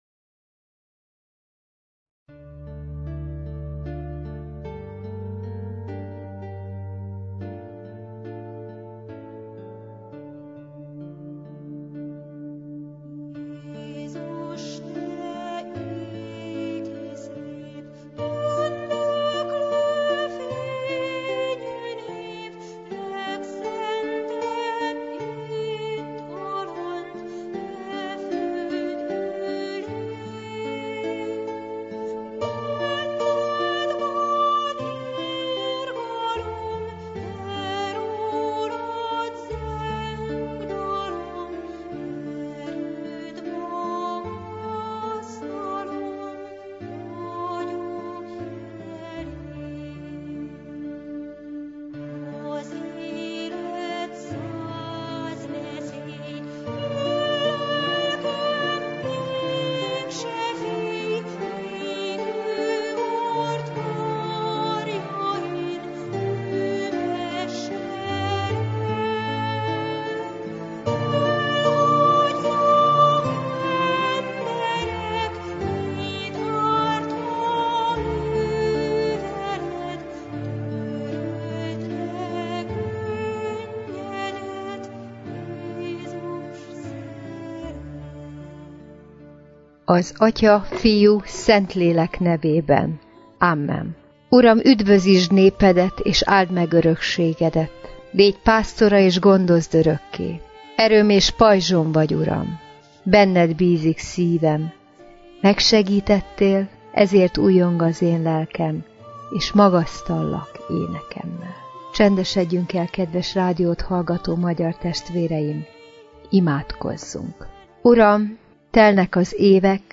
Igét hirdet
evangélikus lelkipásztor.